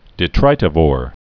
(dĭ-trītə-vôr)